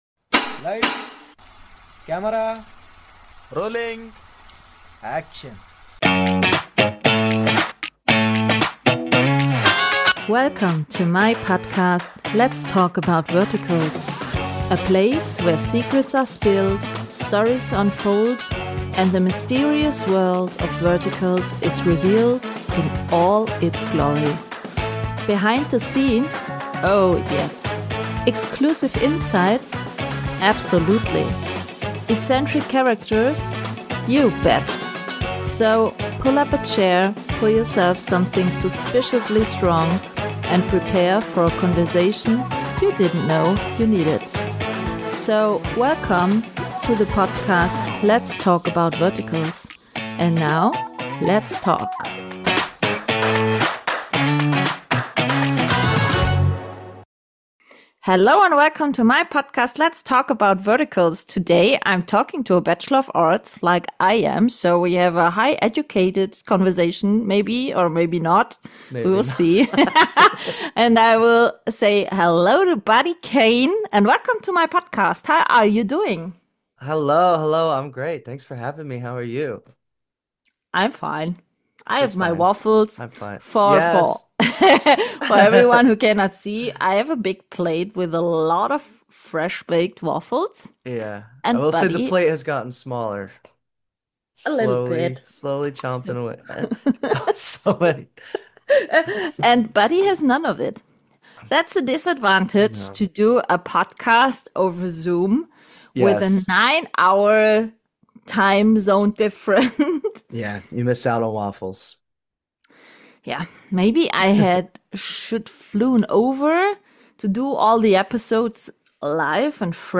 In this episode, you’ll hear a super fun and down-to-earth conversation between two Bachelor of Arts grads .